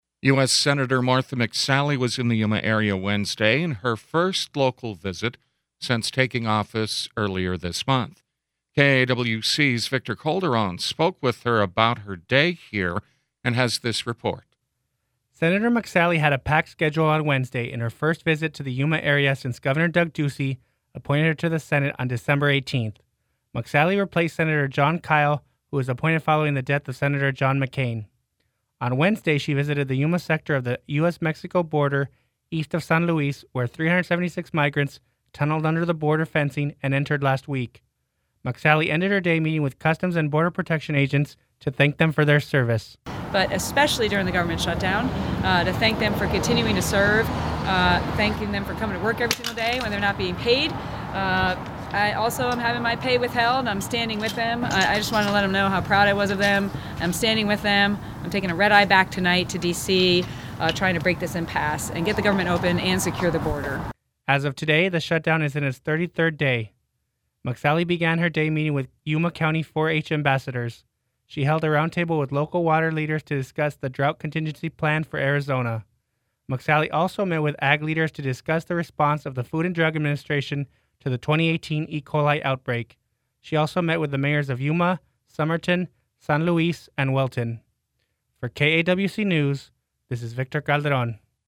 Senator Martha McSally speaks to reporters in front of the U.S. Customs and Border Protection Yuma Station on Wednesday, Jan. 23, 2019.